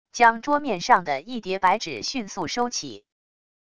将桌面上的一叠白纸迅速收起wav音频